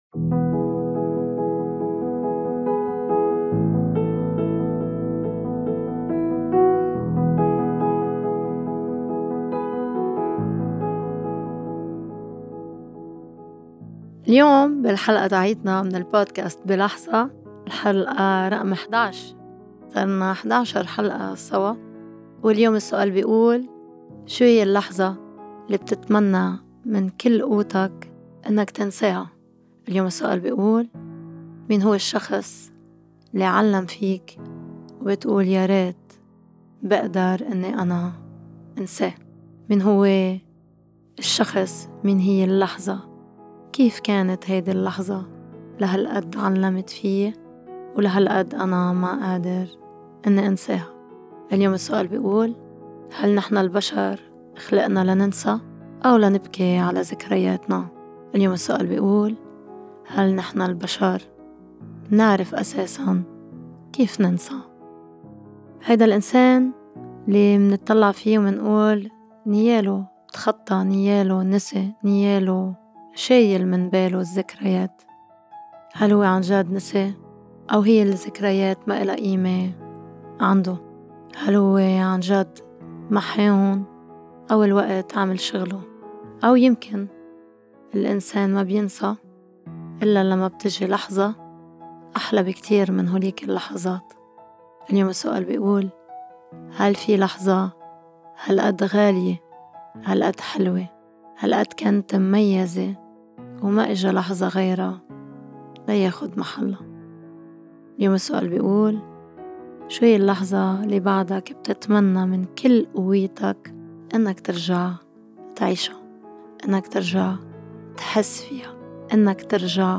الجزء الثاني: مناقشة مع الضيف حول (خيار الشب للبنت وهل ممكن يعترف بالبنت؟ )